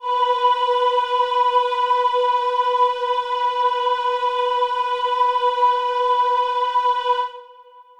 Choir Piano (Wav)
B4.wav